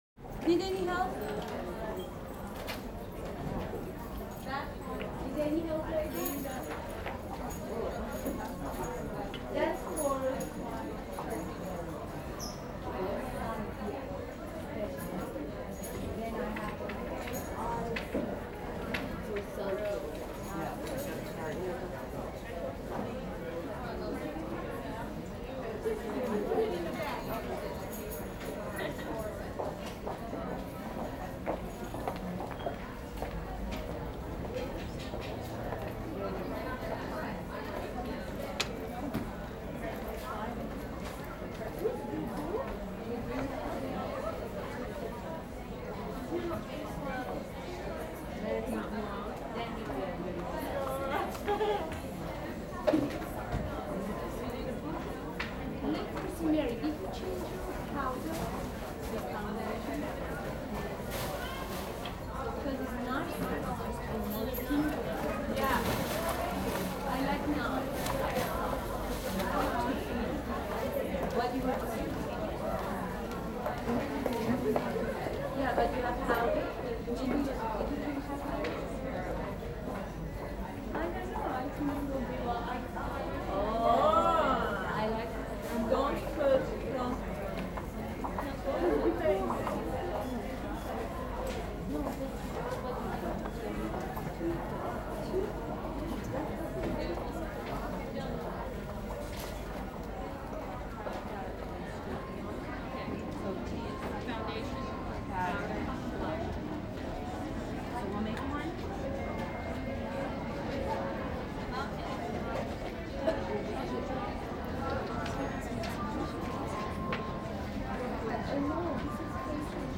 ambience
Department Store Ambience - Light Activity